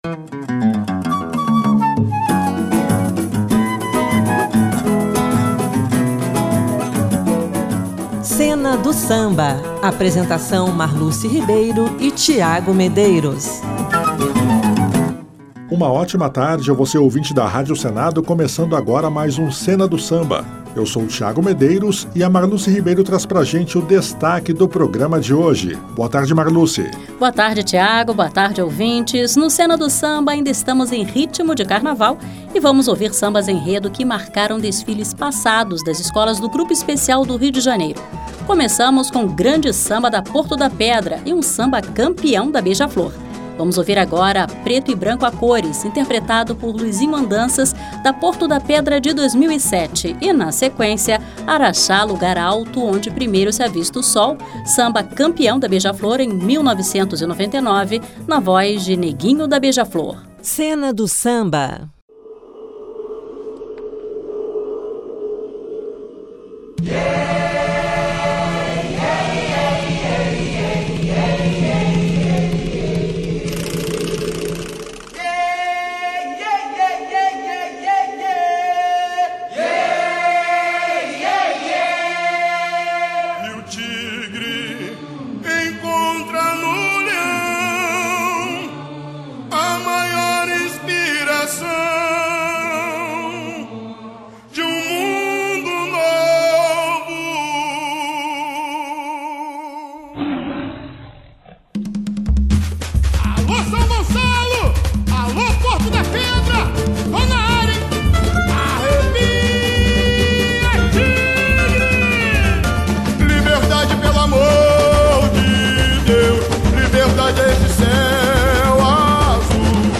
Sambas-enredo históricos das escolas do Grupo Especial RJ